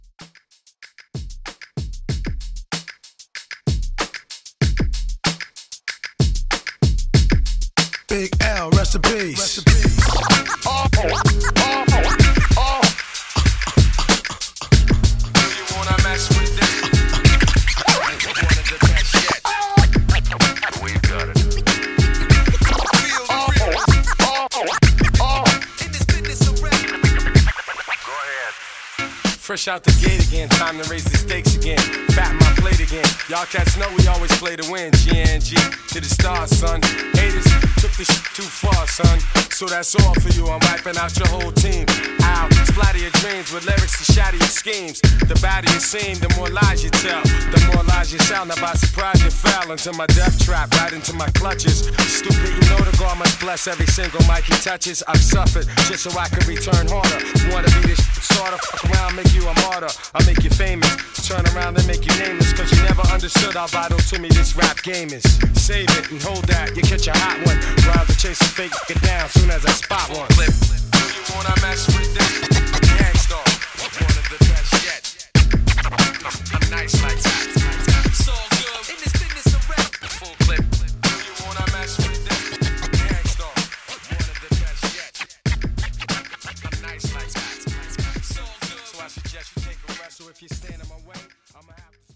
Genre: 60's